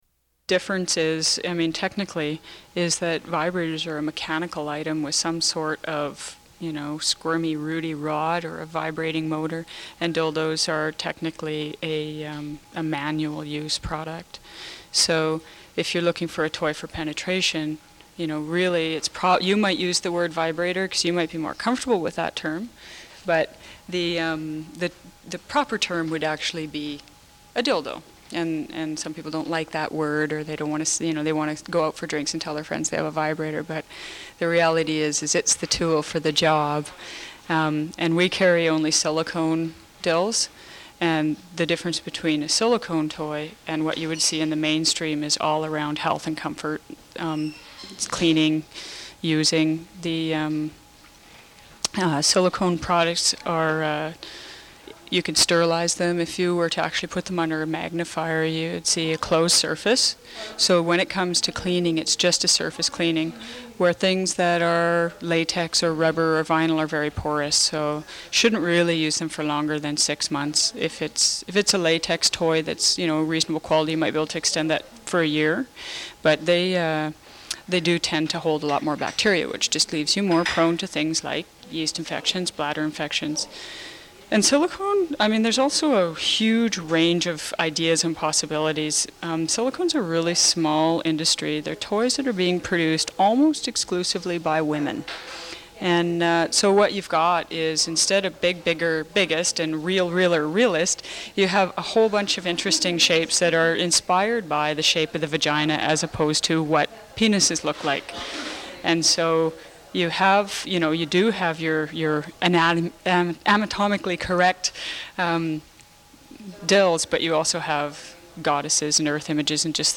Recording of an interview with an employee of the Vancouver-based adult retailer Womyns' Ware, on the topic of sex toys.